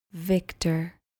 Pronounced: VIK-tah